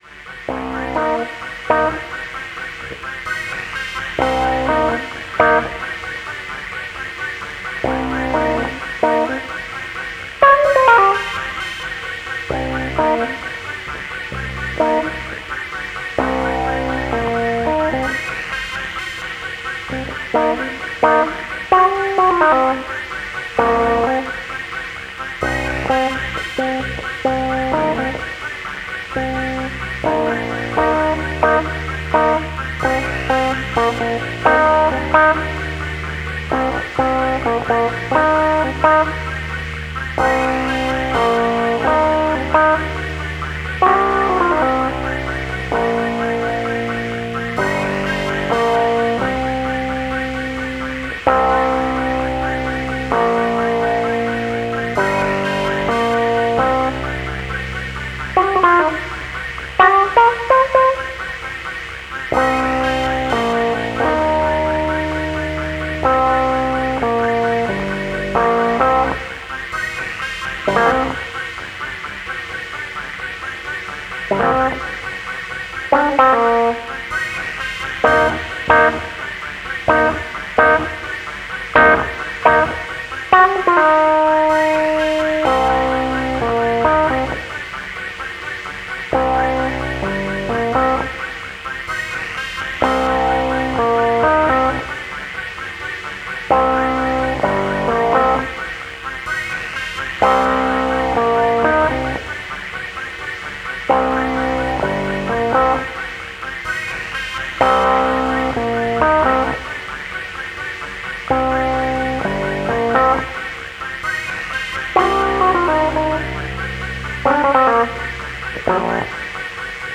Electro jazzy Pulsierend.